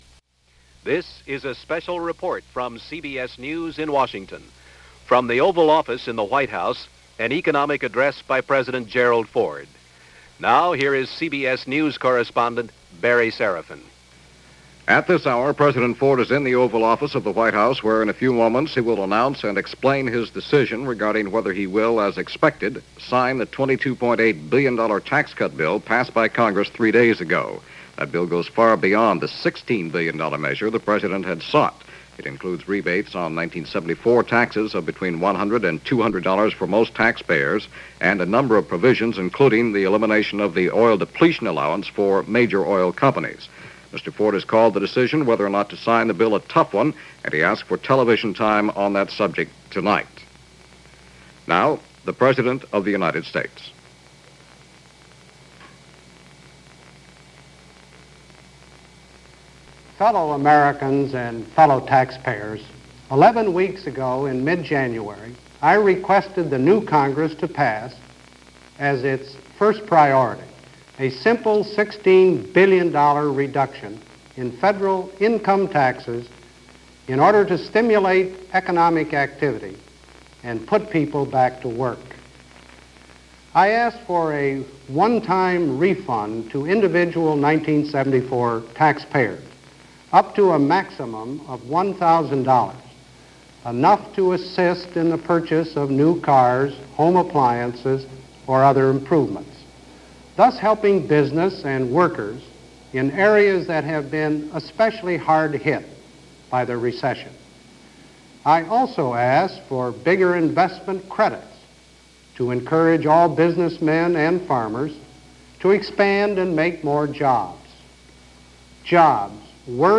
Ford Talks Tax Cuts - President Ford address and signing of the Tax Cut Bill. Reports and reactions from Congress.